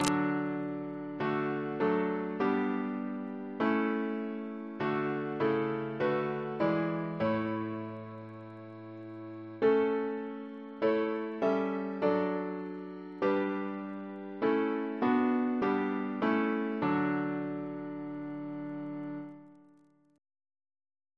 Double chant in D Composer: Henry G. Ley (1887-1962) Reference psalters: ACB: 263